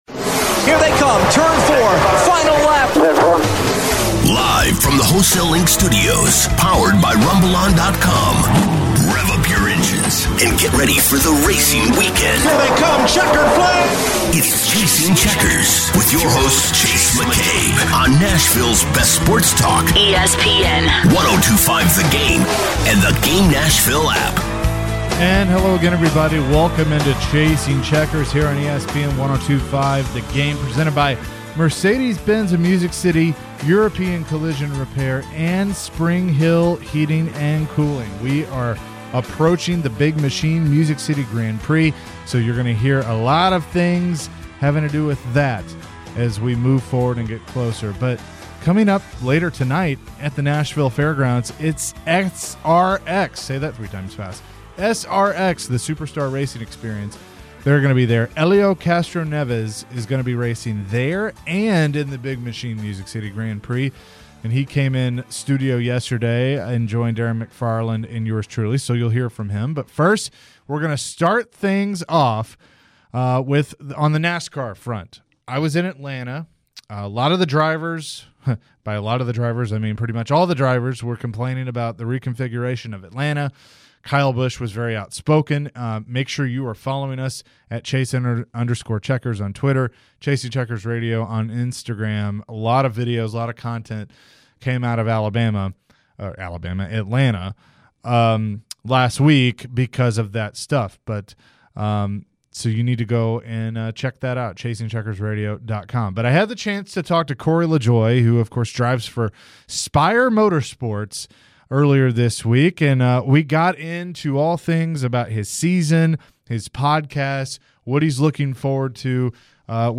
Todays show guests are Corey LaJoie (#7 Nascar Driver), Helio Castroneves (Indy Car Champion) & Michael Waltrip (Nascar Driver & Fox Nascar).